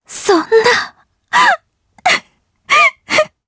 Laudia-Vox_Sad_jp.wav